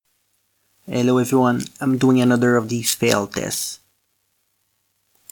I added Effect > Amplification and Effect > Noise Gate.
So that’s Mosquito Killer, Noise Reduction, Amplification, and Noise Gate.
You can do that to every show, but you’re going to spend more time patching the sound than performing, and it does leave little noises behind your voice.